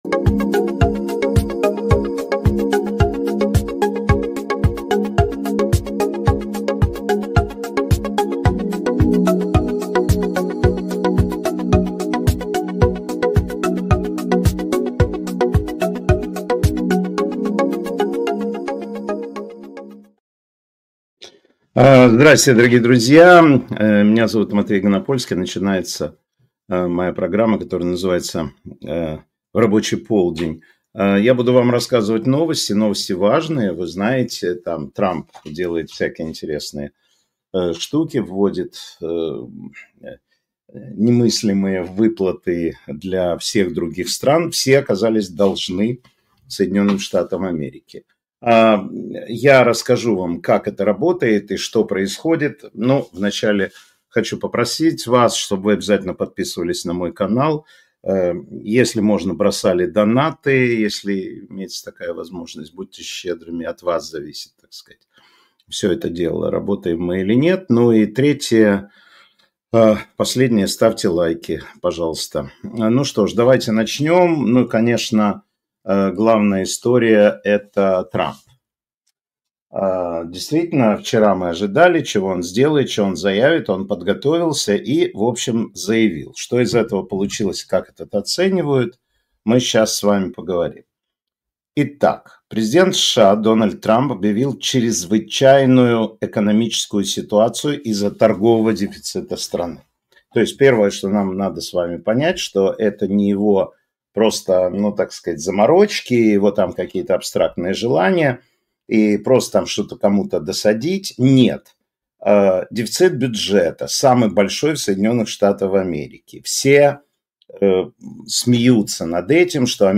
Эфир ведёт Матвей Ганапольский